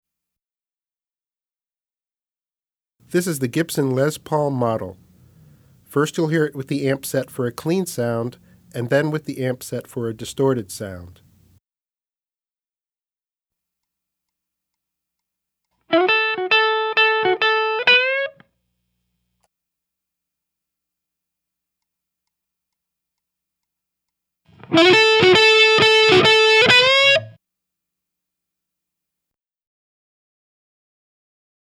Voicing: Guitar w/C